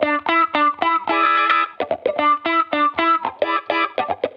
Index of /musicradar/sampled-funk-soul-samples/110bpm/Guitar
SSF_StratGuitarProc1_110E.wav